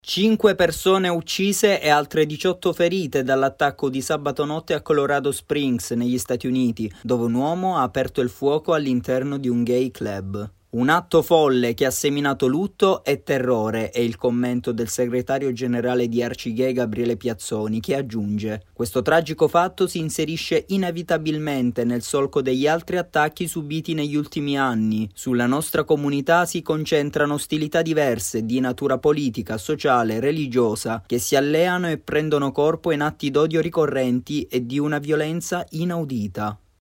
servizio-internazionale.mp3